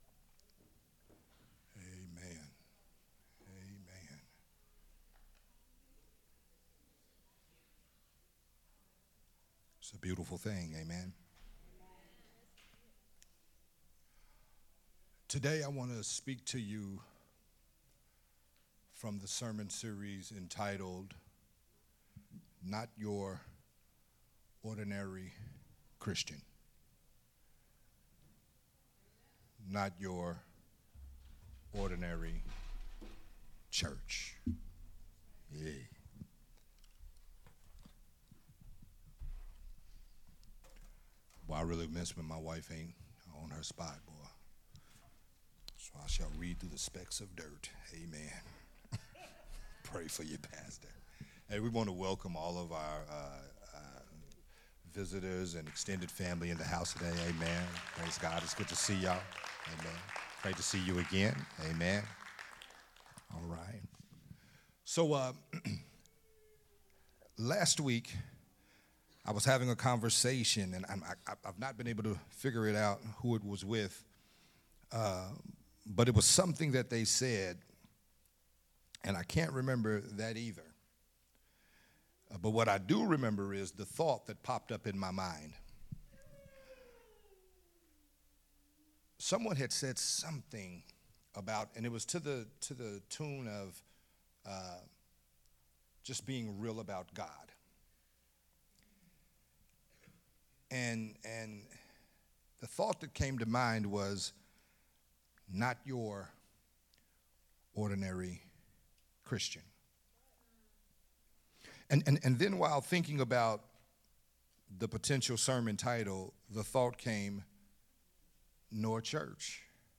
a Sunday Morning sermon
recorded at Unity Worship Center on November 5th, 2023.